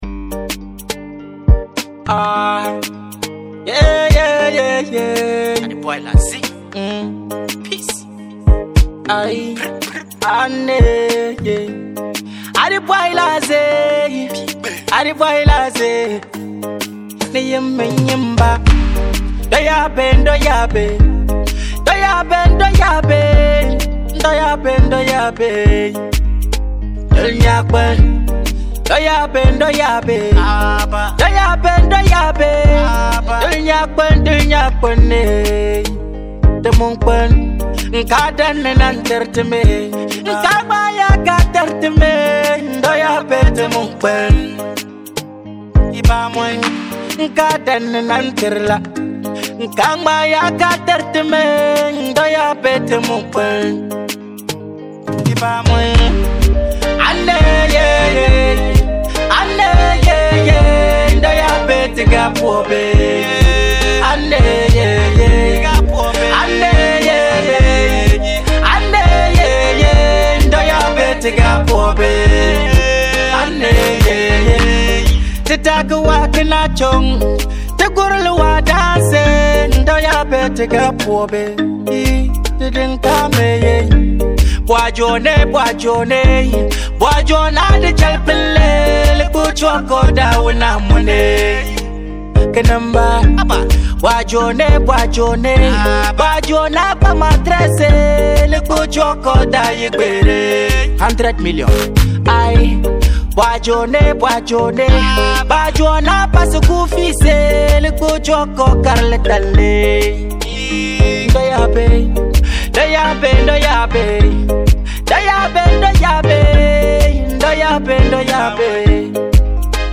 With its addictive flow and rich sound
Most of his songs are Afrobeat and Dancehall.